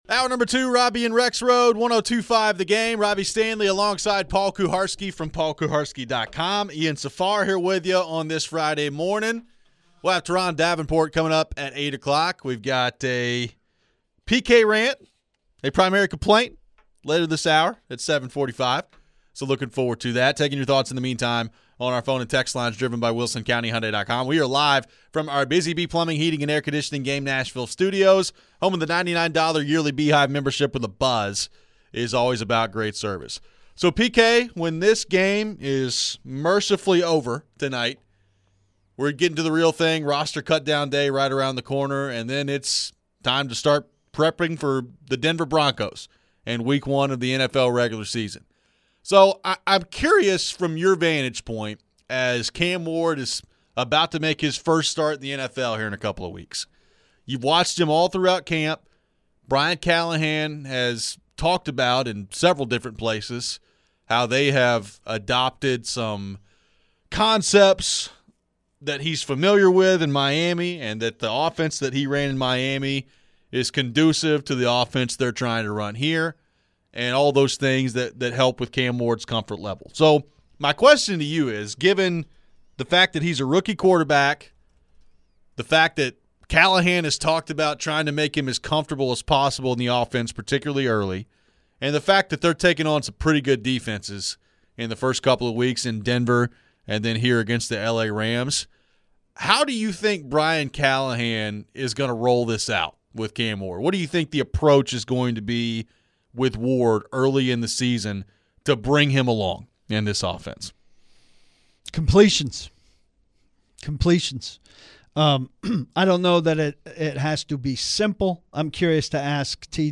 How can Brian Callahan and the Titans set Cam Ward and the offense up for success in year two of the system? How key will it be to find a legit 2nd option in the passing game behind Ridley? We head to your phones.